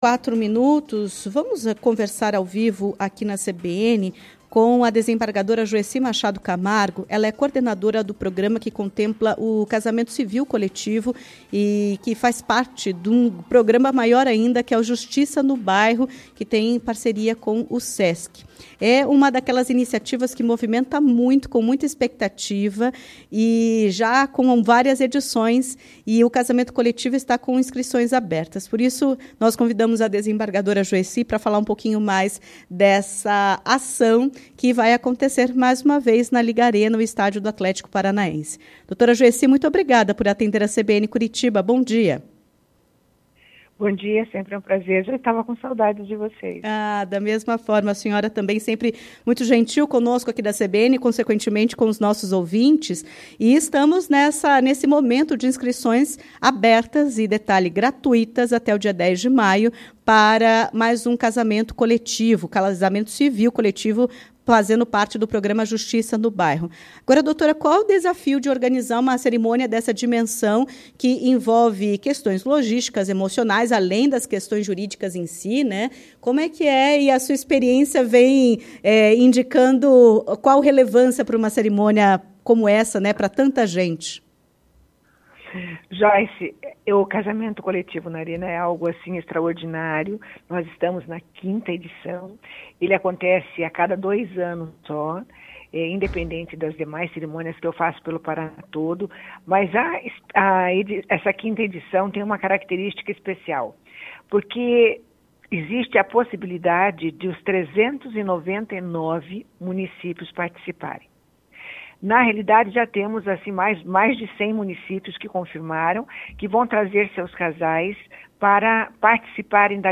A desembargadora Joeci Machado Camargo, coordenadora do programa, será a responsável por conduzir a cerimônia e conto mais à CBN Curitiba sobre como funciona e os 22 anos do programa Justiça no Bairro.